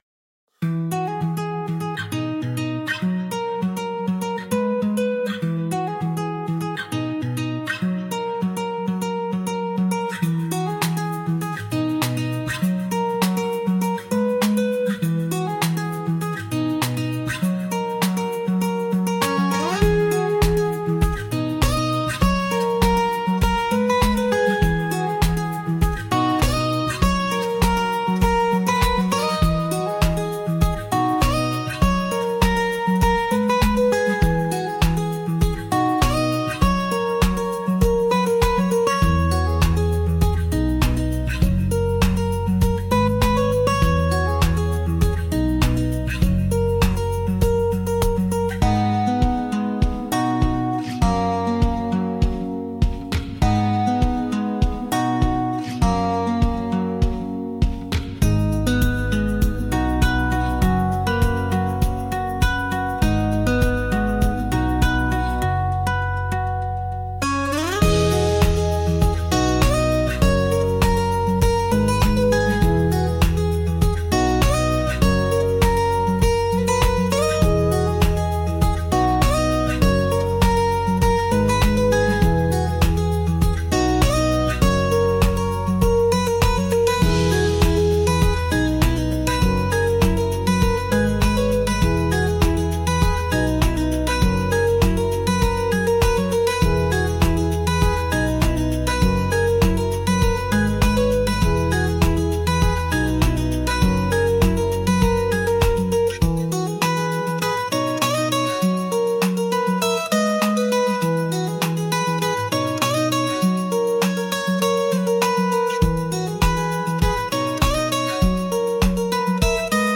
B Major – 100 BPM
Acoustic
Hip-hop
Pop
Rnb